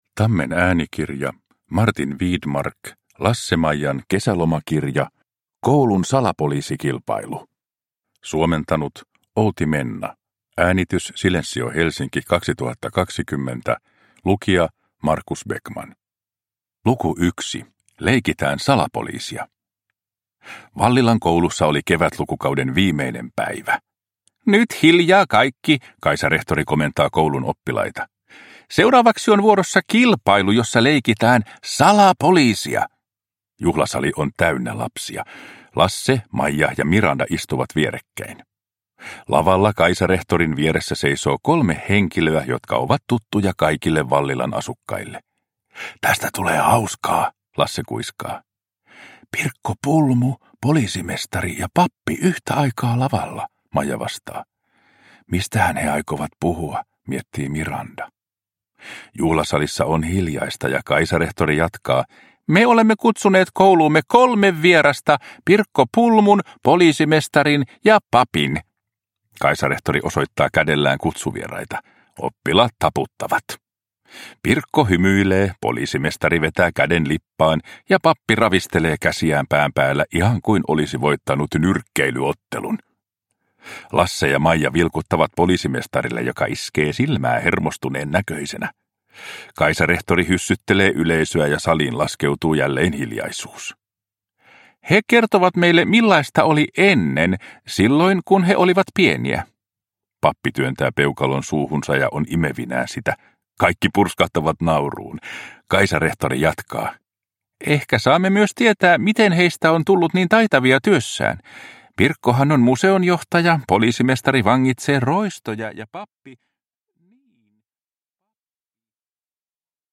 Lasse-Maijan kesälomakirja 1 – Ljudbok – Laddas ner